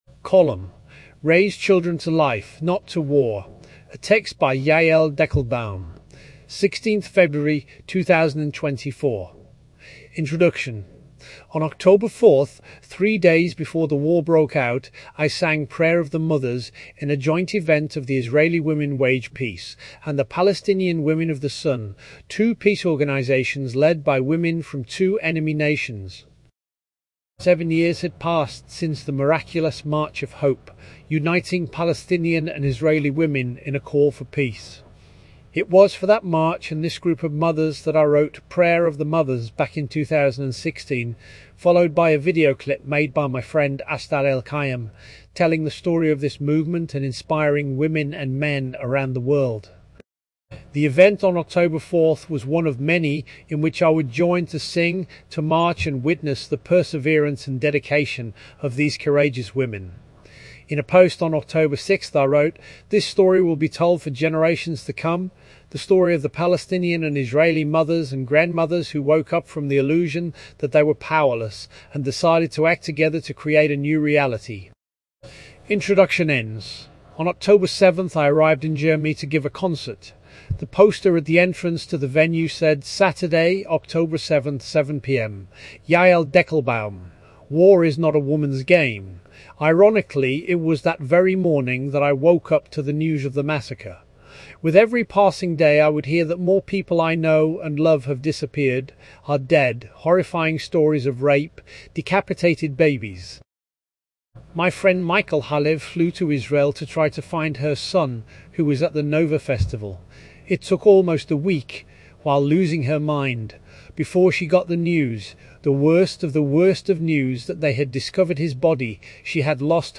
This tech is still very new, please don't expect it to be perfect.